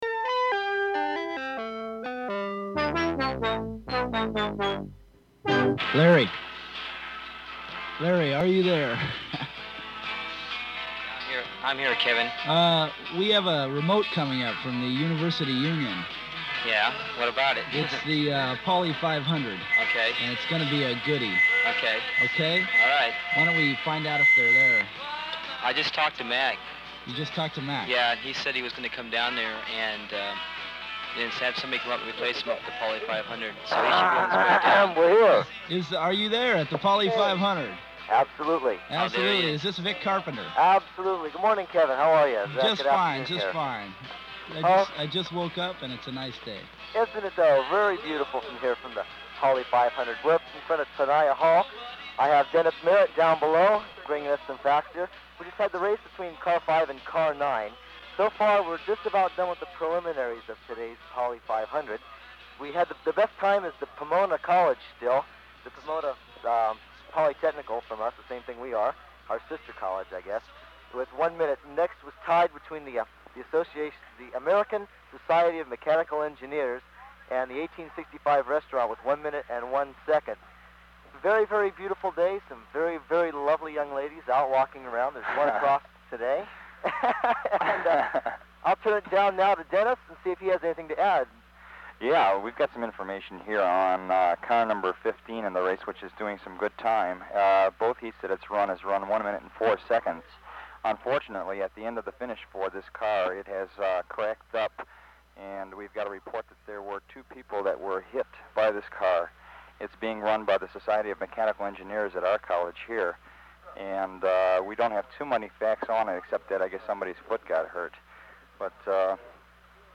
Form of original Open reel audiotape